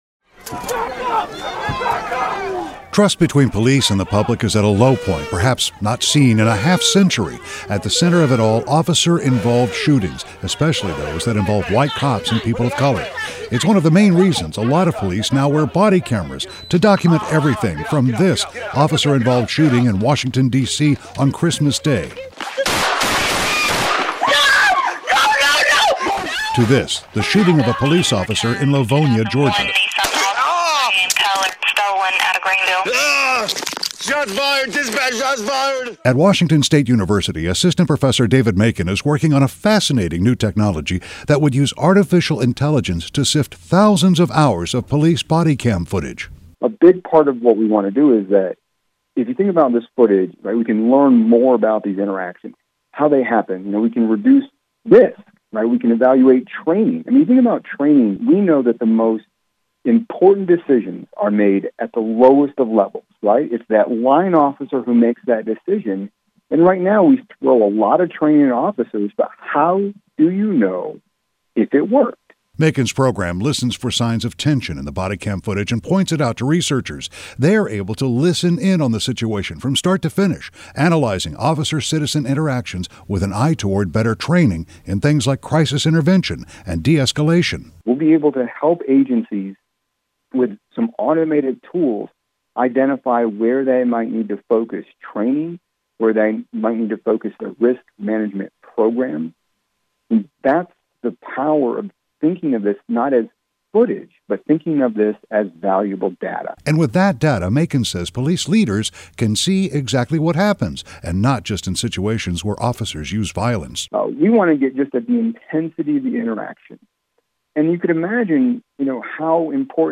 KOMO News Interview – Click to Listen PoliceOne – How body camera footage can enhance officer training